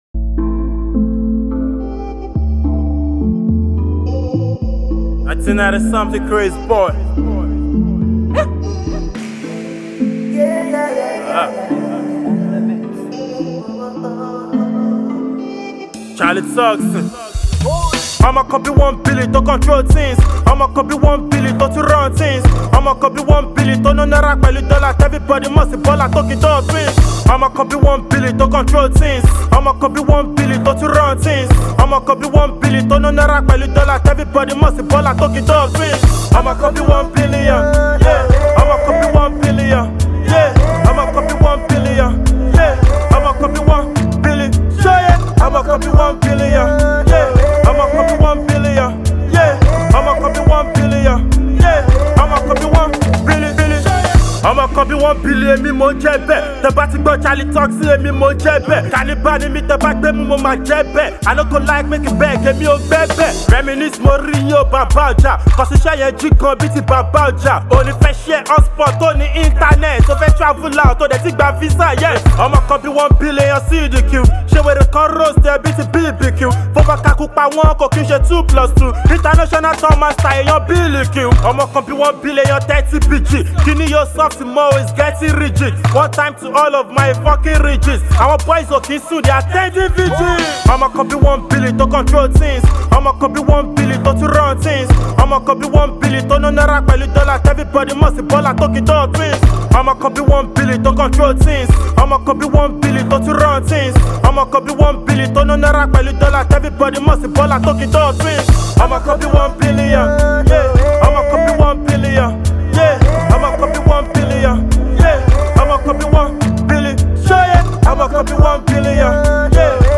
Afro-pop
the young sensational rapper